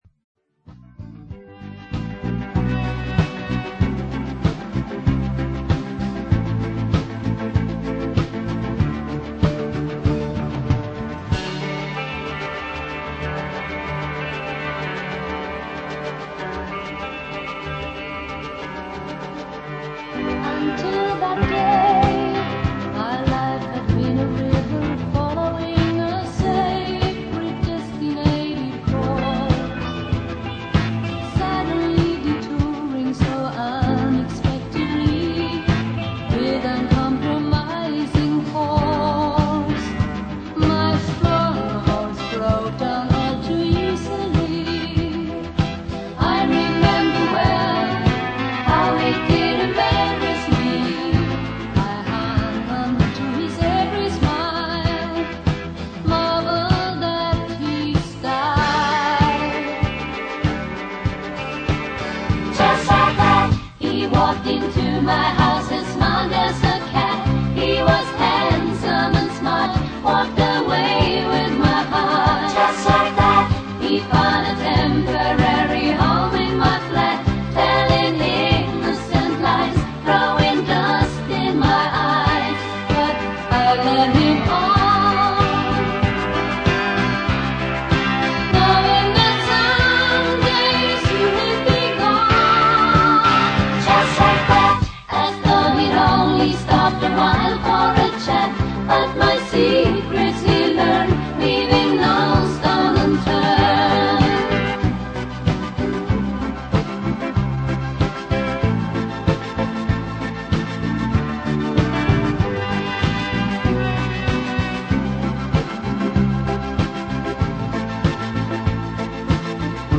showing us a great sax, that it's one of the best features